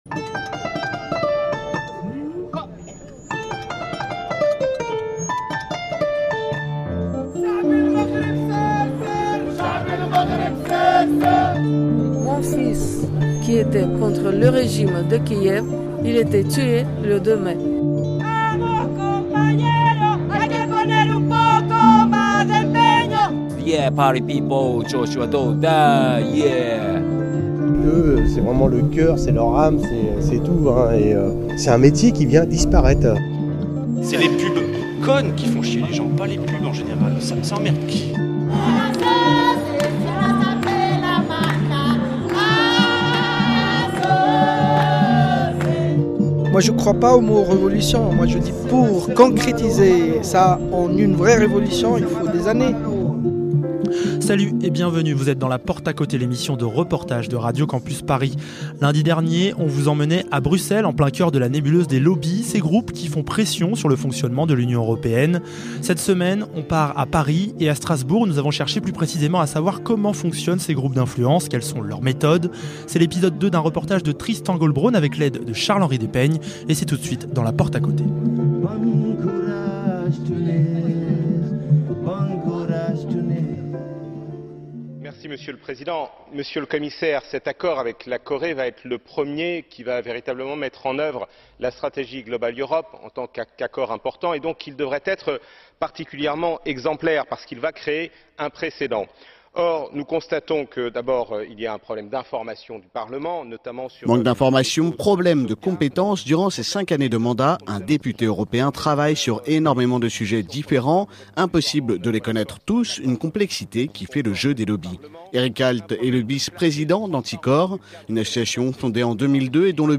Dans ce second et dernier épisode de notre reportage sur les lobbys, La Porte à Côté à choisit de s'intéresser à leur pratiques et s'est rendue à Strasbourg, à la rencontre de lobbyistes et de parlementaires.